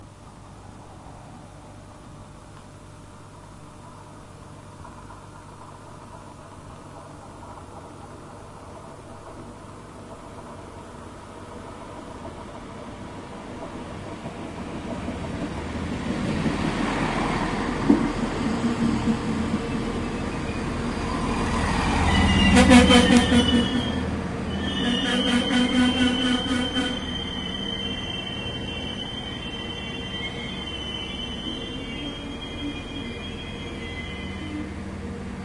火车 " 荷兰火车来了1
描述：一列火车来到荷兰的Zutphen车站。你可以清楚地听到车轮和柴油机旁的休息声。
Tag: 场记录 断线 铁路 列车